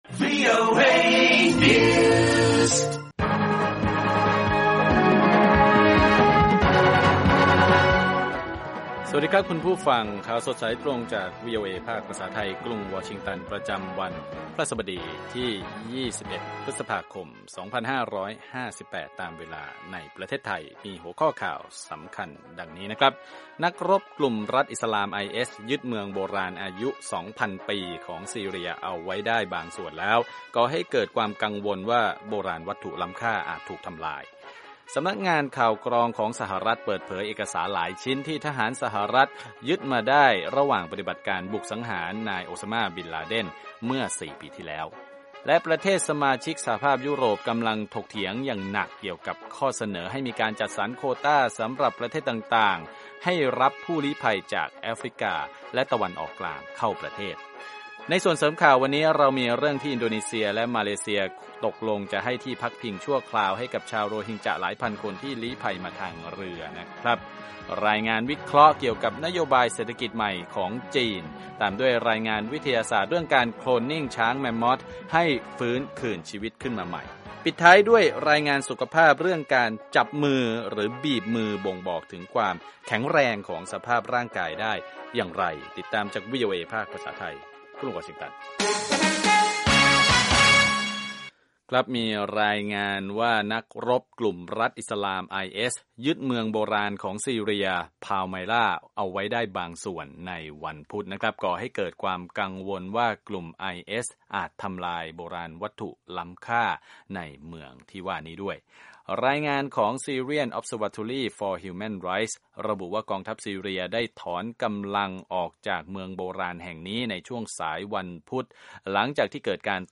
ข่าวสดสายตรงจากวีโอเอ ภาคภาษาไทย 8:30–9:00 น. พฤหัสบดีที่ 21 พฤษภาคม 2558